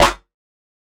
Snare 010.wav